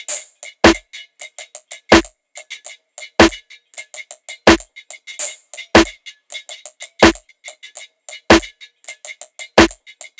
Op Jangler Perc Loop.wav